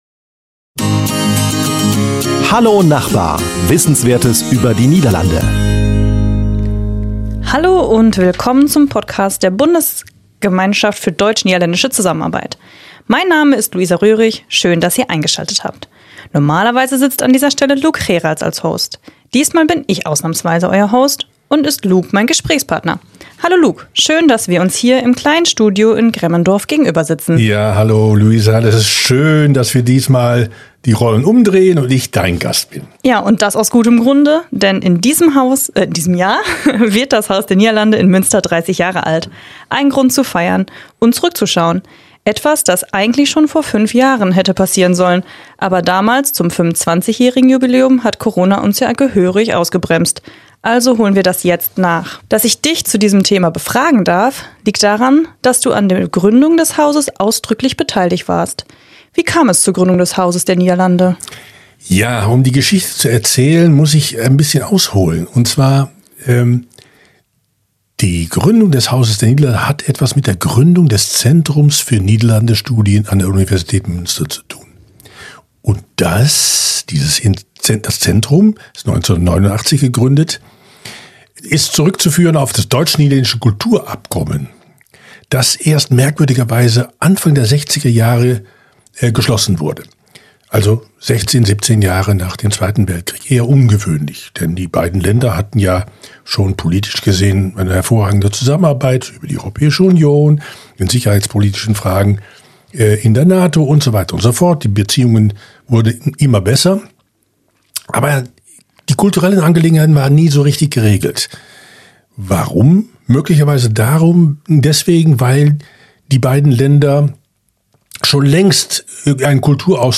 In dieser Folge befragt unser Gasthost